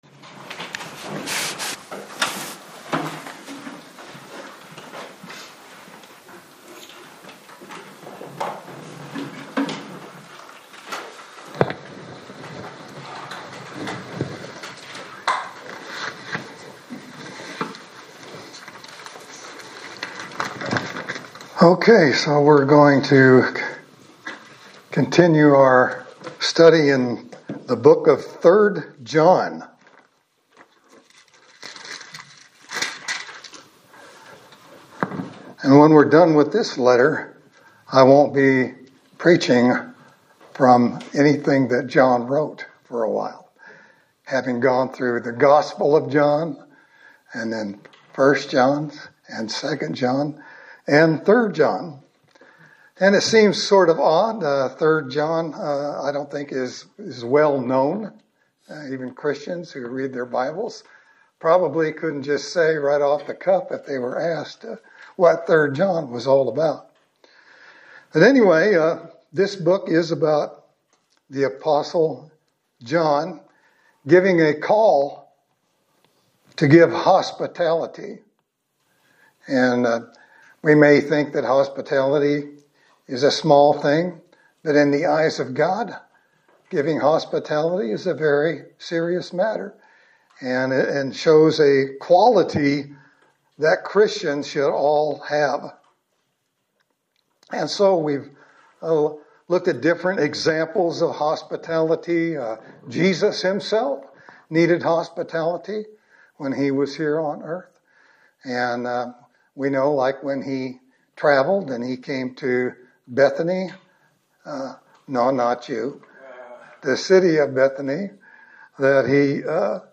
Sermon for November 2, 2025
Service Type: Sunday Service